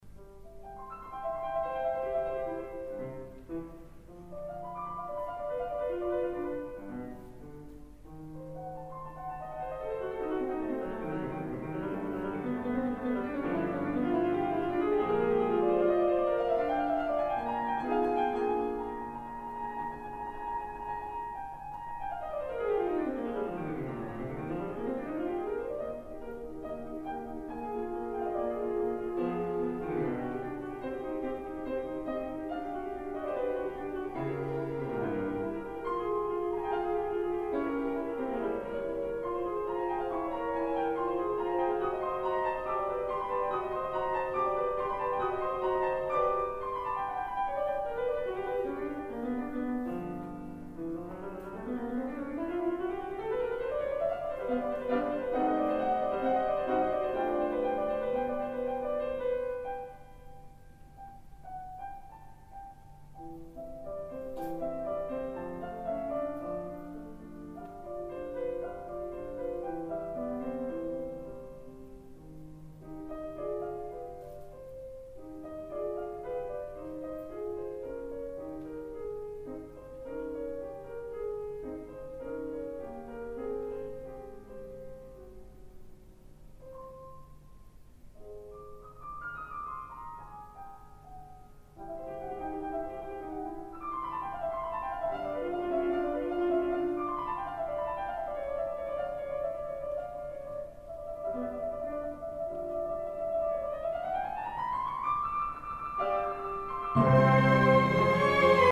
Cadenza
11:08pm 2 fuck!! da harsh cut on end!